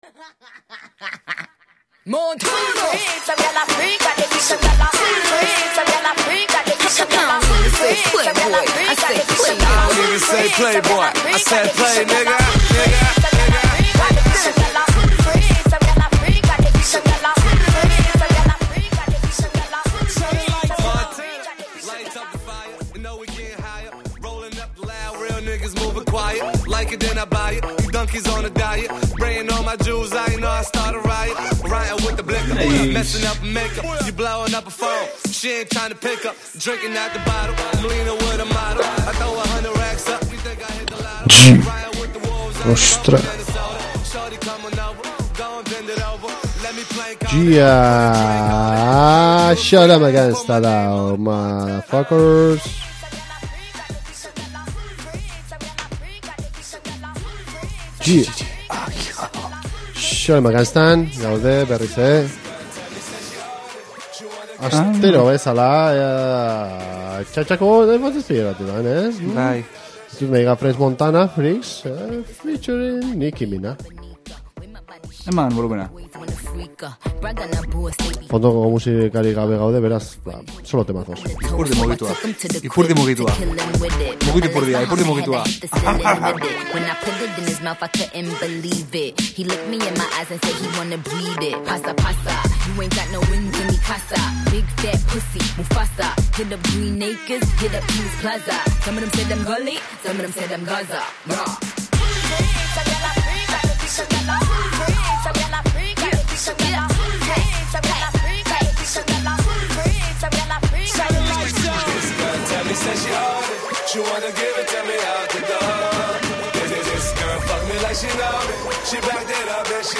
Euskal Herriko eta nazioarteko rap musika izan da entzugai Xolomo Gangsta saioan.